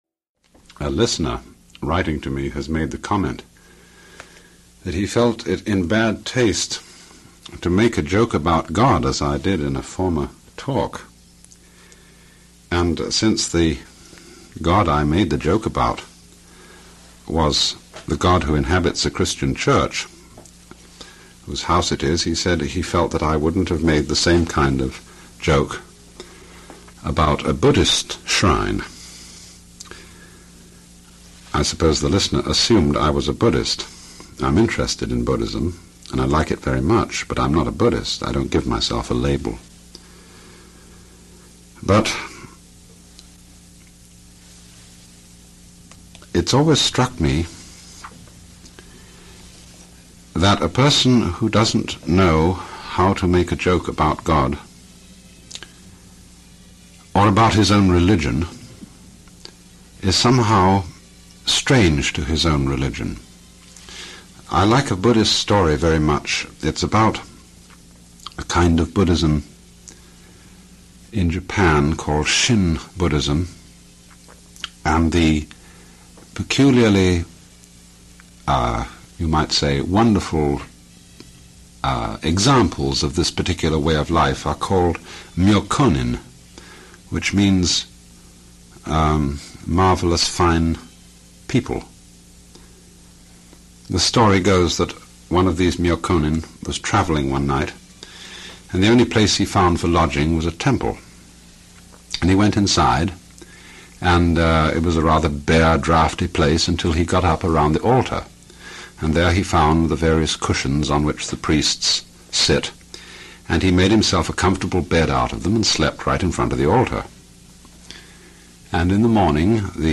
Alan Watts – Early Radio Talks – 12 – Humor in Religion